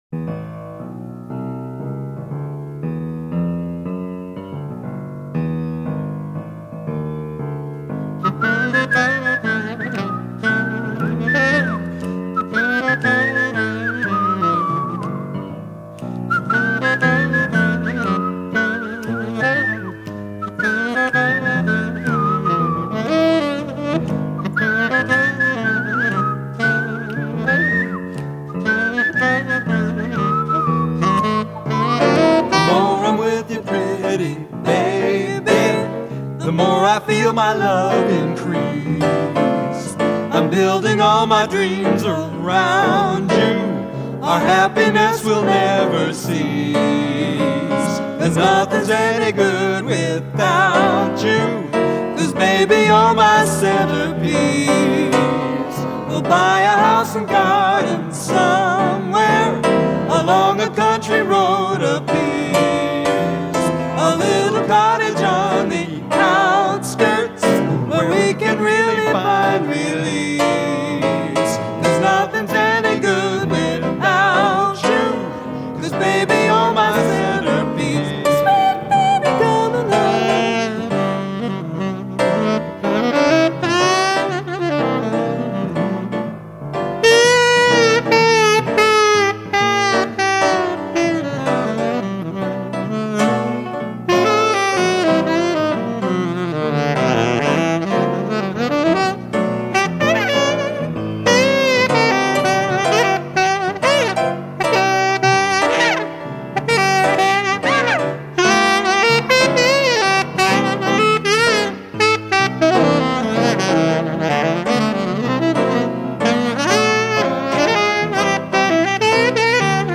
Vocals
Harmonica, Piano, Sax